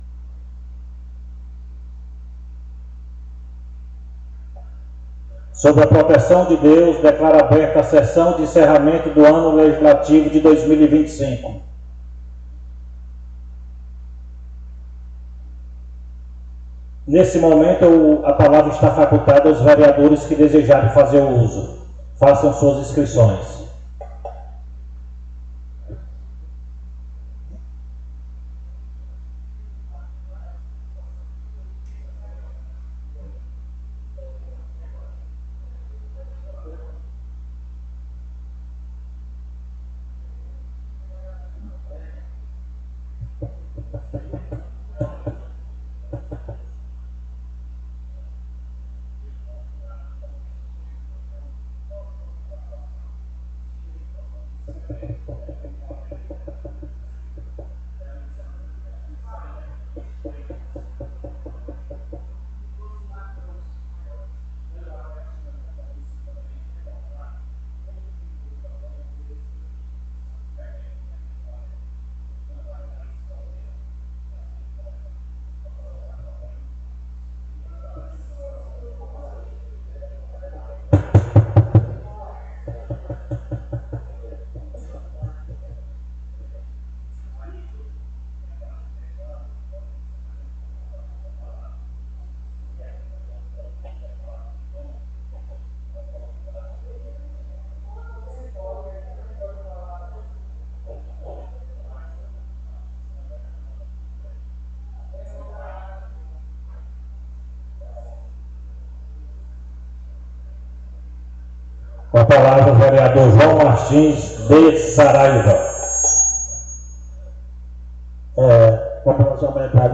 Mídias Sociais 10ª SESSÃO ORDINÁRIA 16 de junho de 2025 áudio de sessões anteriores Rádio Câmara A Sessão da Câmara de Vereadores ocorre na segunda-feira, a partir das 19:30h.